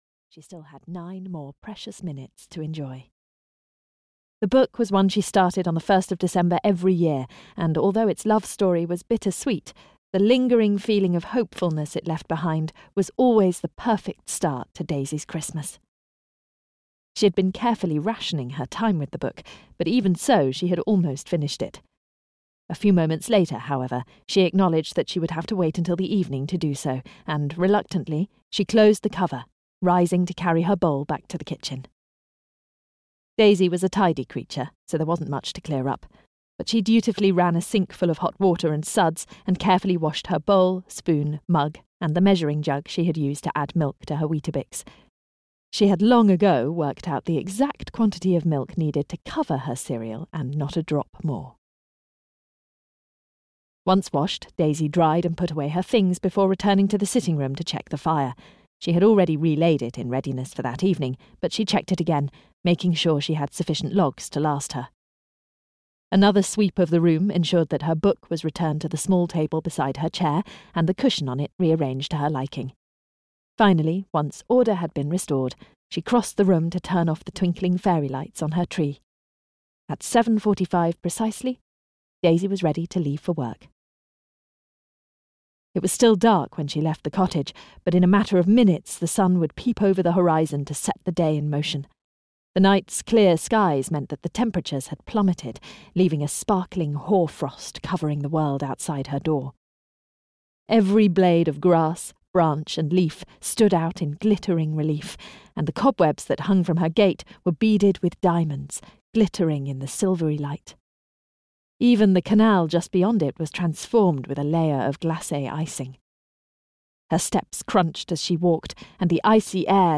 Audio knihaThe Little Shop on Silver Linings Street (EN)
Ukázka z knihy